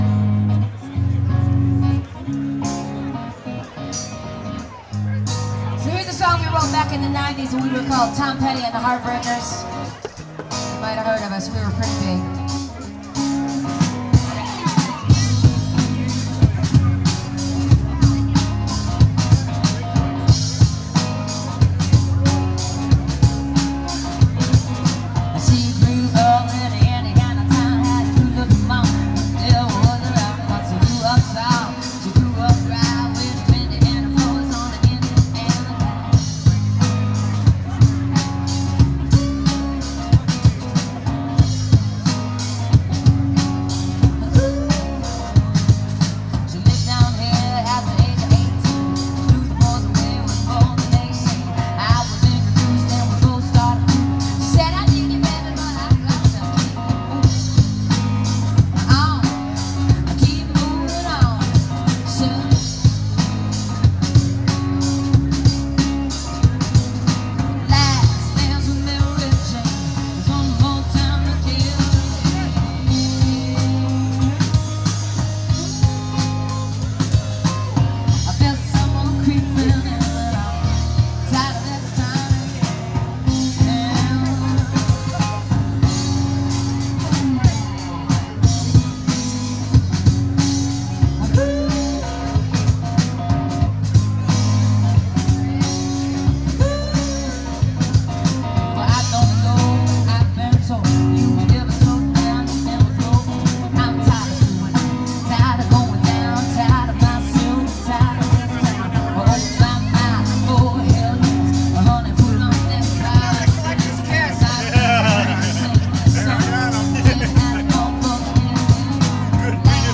An excerpt from a performance
indie pop duo